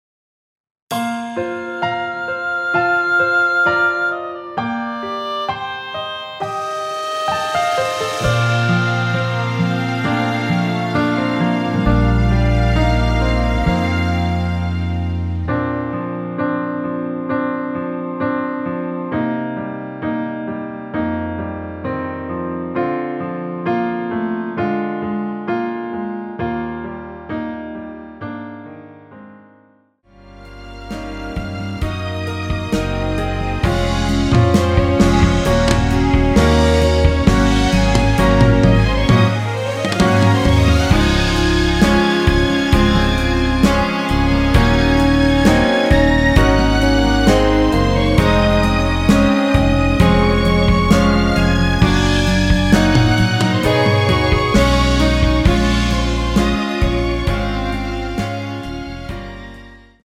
원키 1절 + 후렴으로 편곡한 MR 입니다.(미리듣기및 가사 참조)
Bb
앞부분30초, 뒷부분30초씩 편집해서 올려 드리고 있습니다.
중간에 음이 끈어지고 다시 나오는 이유는